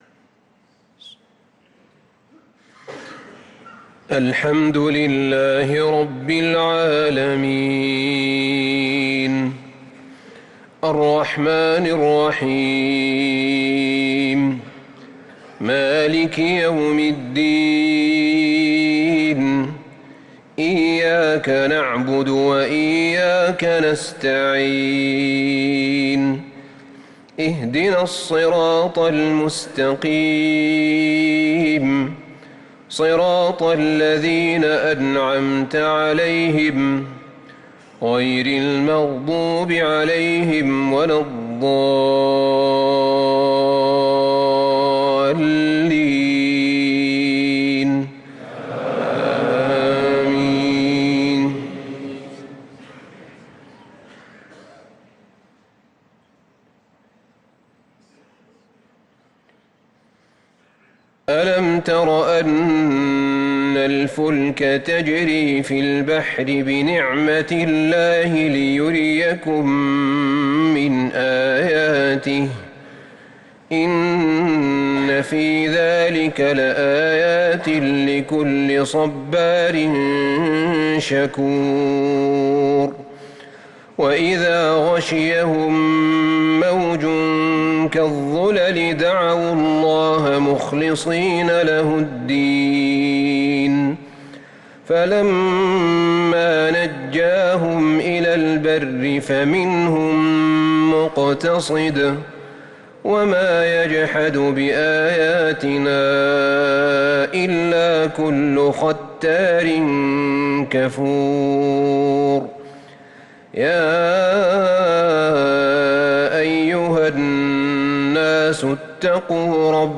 صلاة العشاء للقارئ أحمد بن طالب حميد 15 جمادي الأول 1445 هـ
تِلَاوَات الْحَرَمَيْن .